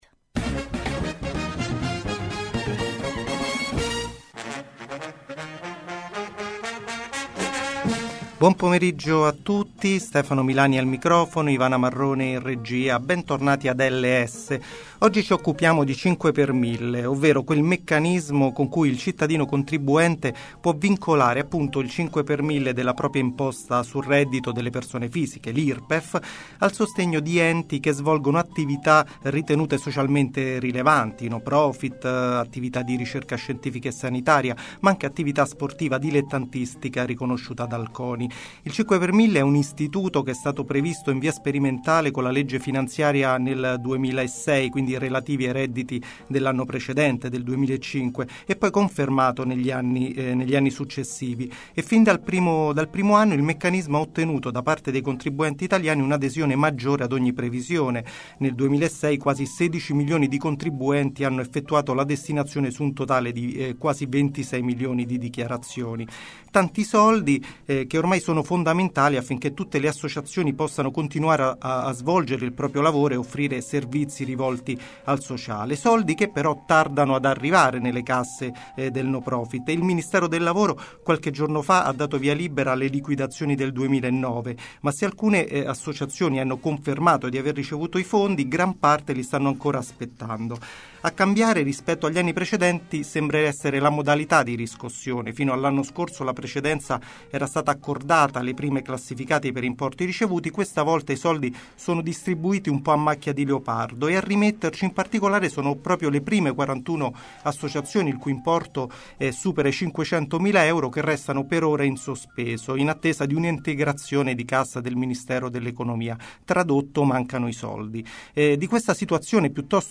Comunicato e intervista al Portavoce